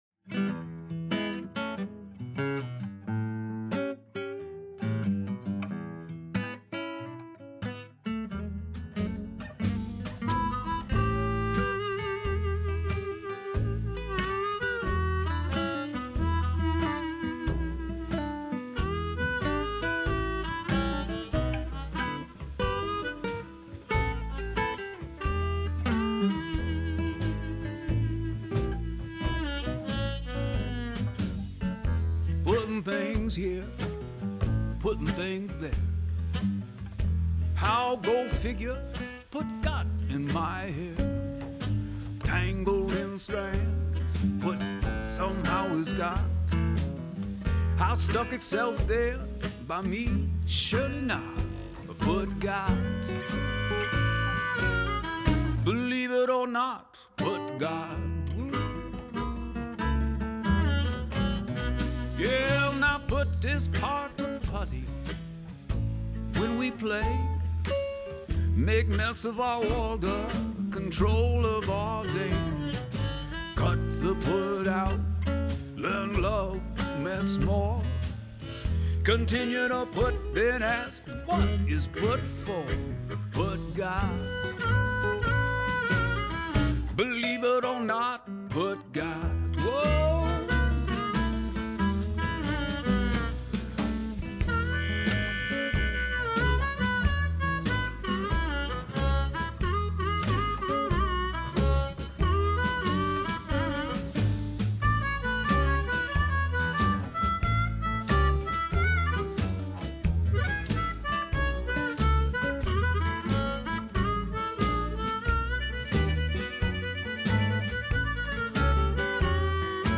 My musical styling can be characterized this way: "original country-urban blues with a delta twist." I'm a guitarist and singer, and a sample of my music is enclosed to inform design ideas.